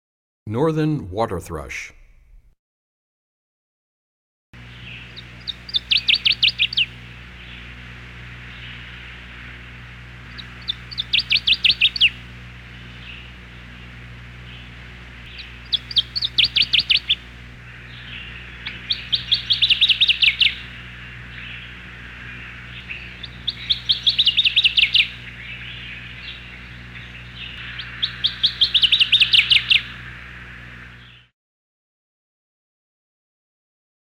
62 Northern Waterthrush.mp3